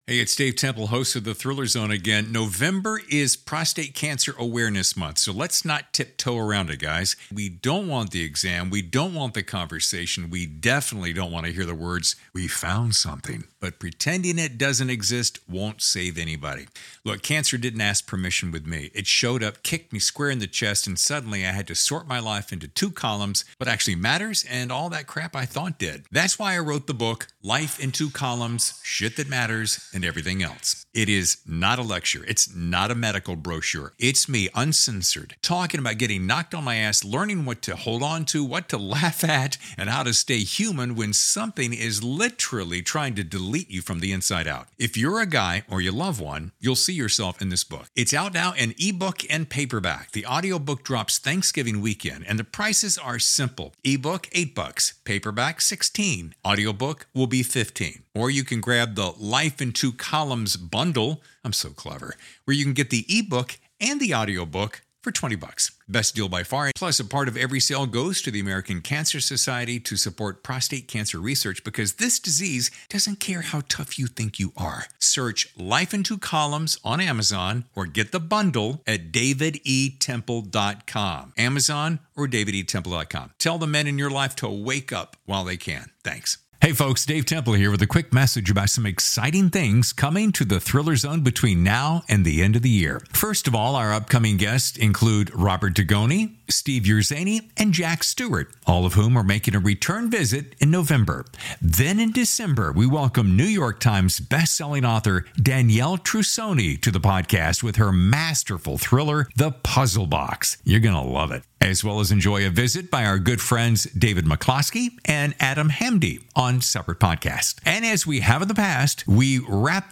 As I said, the moment was spontaneous, the episode is shorter than most, but it was an experiment of sorts to see how interviewing and producing on the fly, but "not exactly live" would work.